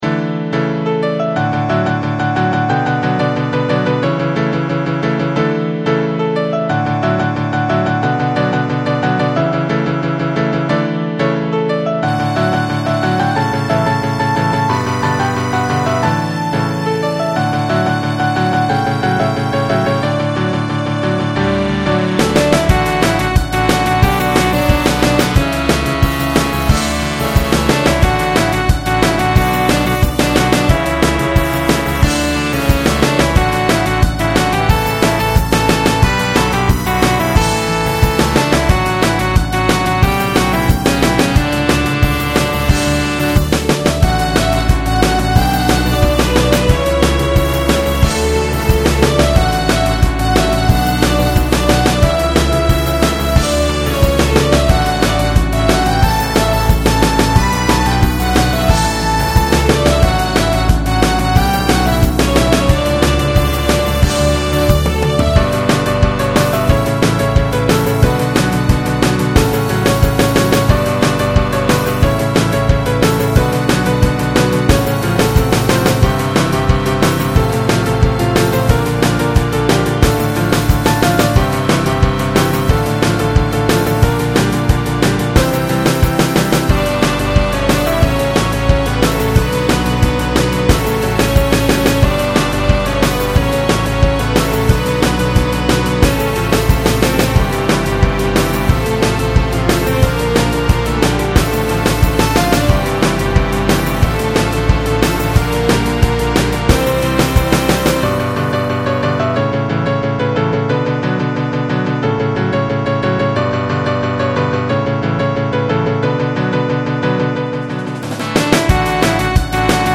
モノラル   悩みましたが、ピアノの音で選びました。ドラムの音もかっこいいので迫力があります。